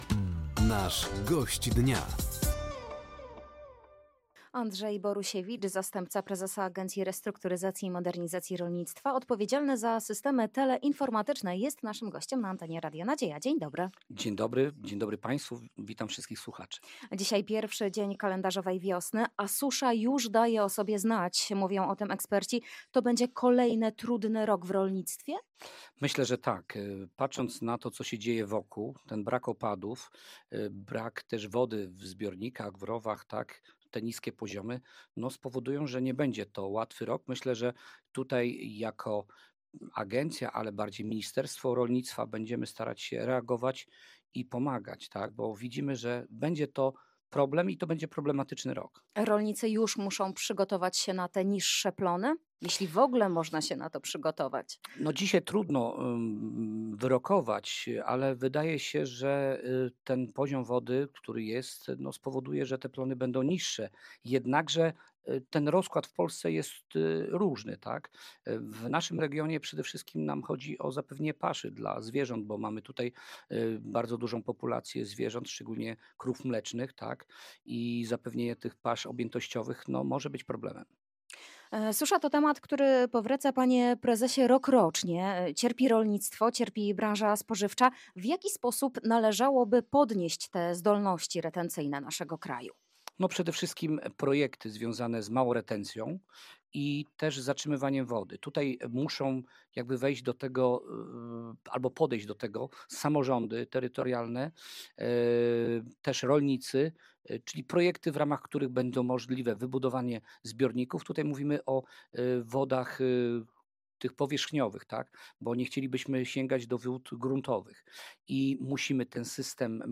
O rolniczych problemach i wyzwaniach mówił Andrzej Borusiewicz, zastępca prezesa Agencji Restrukturyzacji i Modernizacji Rolnictwa.